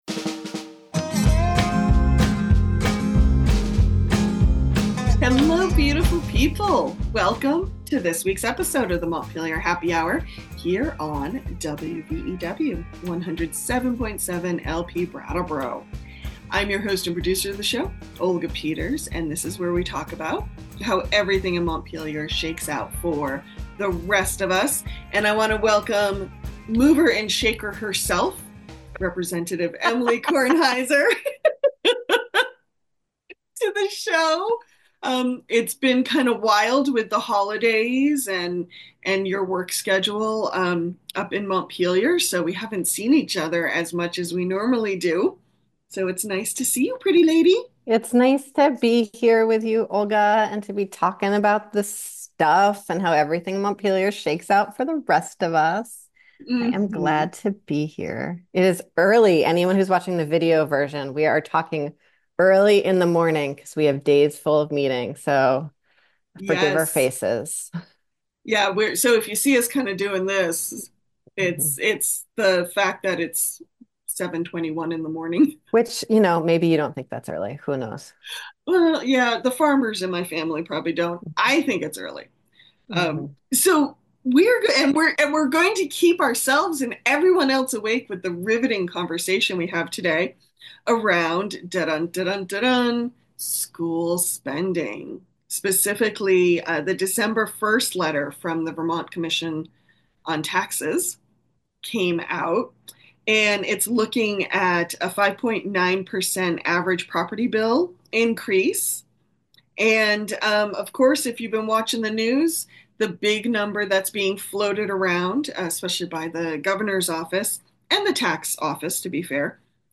The Montpelier Happy Hour's Regular Contributor and resident tax expert, Representative Emilie Kornheiser, explains what all this means.